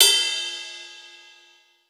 J_RIDE_CUP.WAV